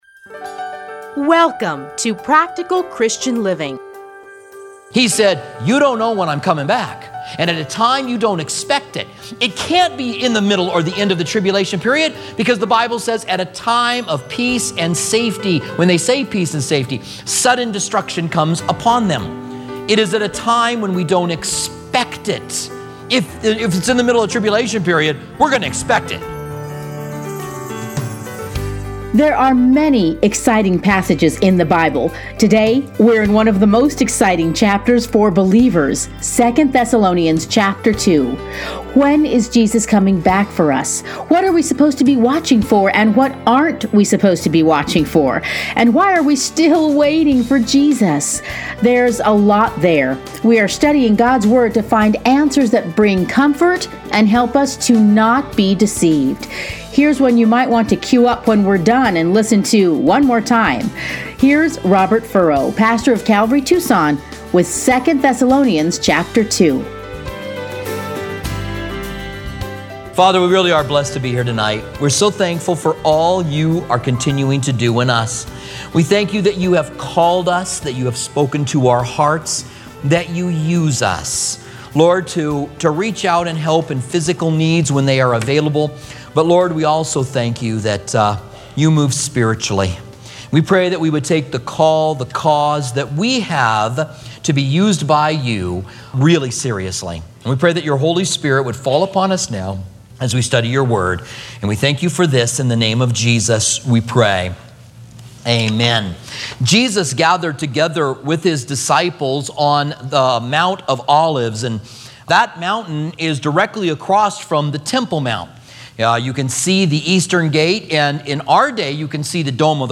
Listen here to a teaching from 2 Thessalonians.